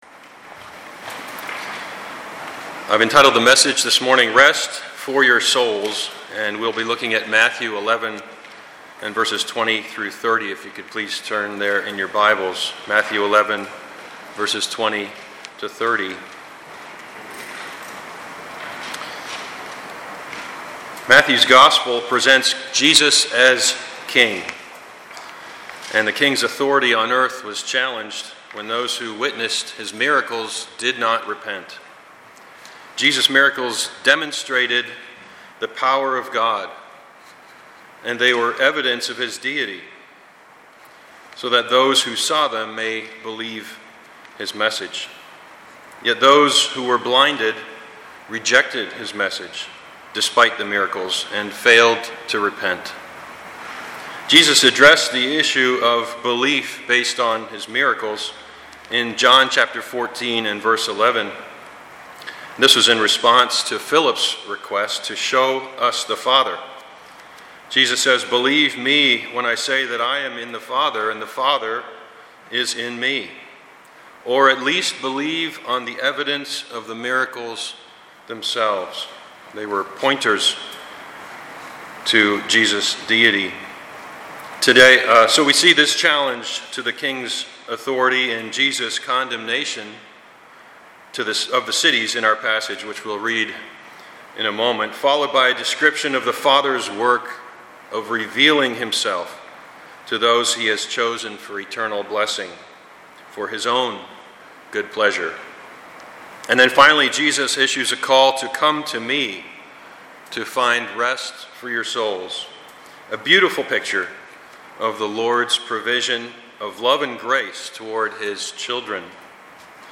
Passage: Matthew 11:20-30 Service Type: Sunday morning